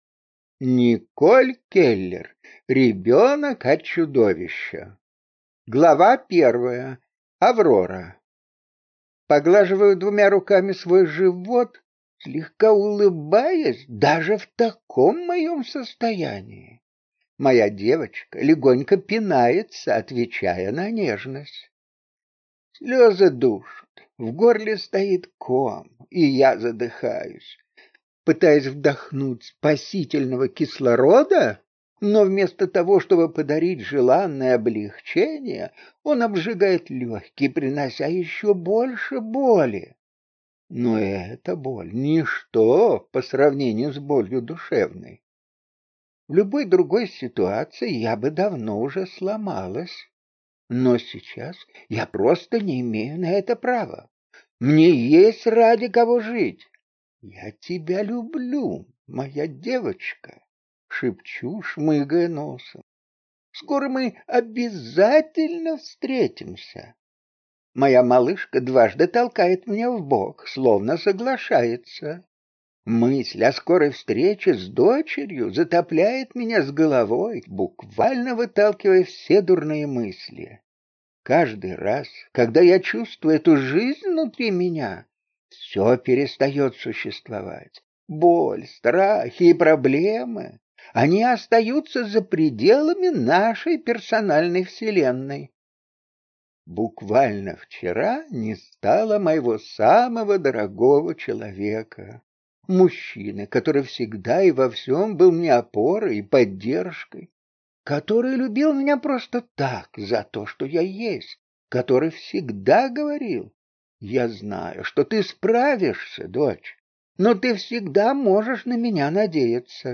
Аудиокнига Ребенок от чудовища | Библиотека аудиокниг